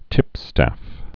(tĭpstăf)